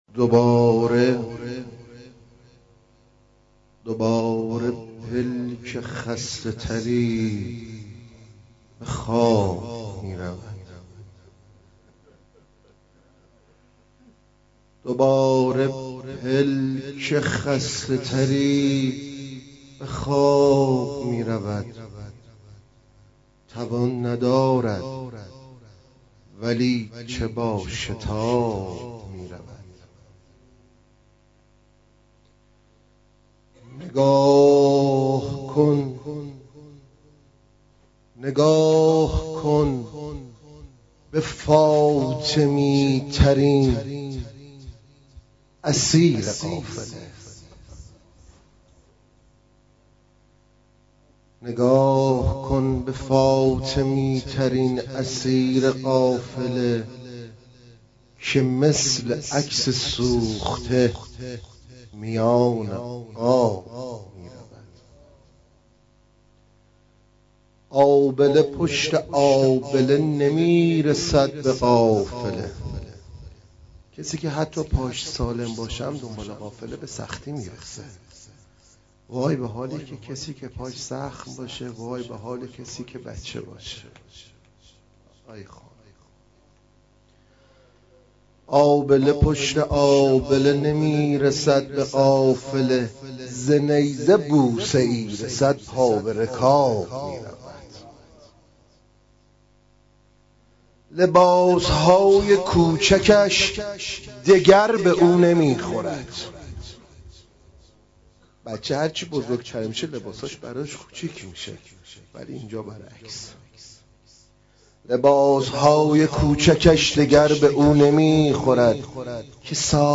شب چهارم سفره حضرت رقیه سلام الله علیها ۹۳/۹/۵ :: هیئت رایة الرضا علیه السلام